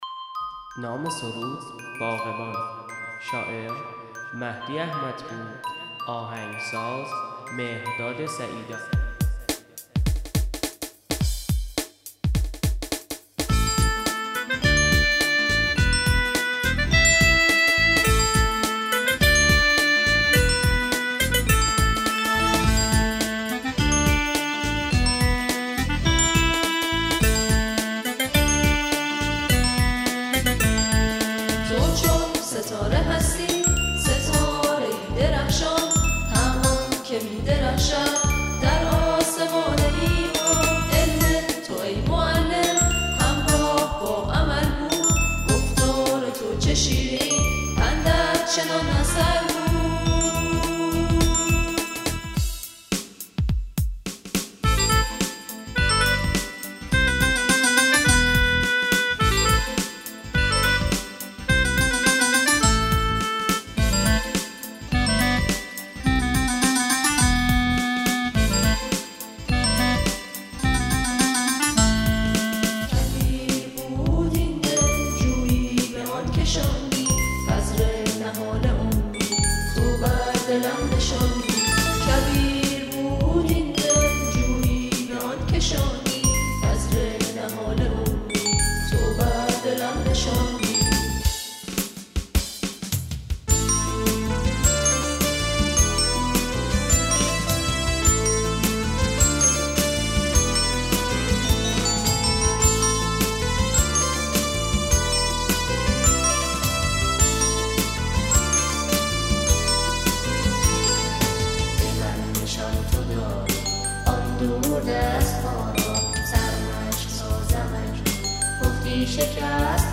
گروهی از جمعخوانان